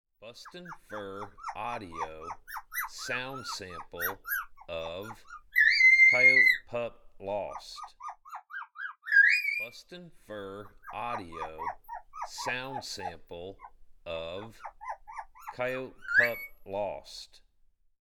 BFA Coyote Pup Lost
Very small Coyote pup in distress.
BFA Coyote Pup Lost Sample.mp3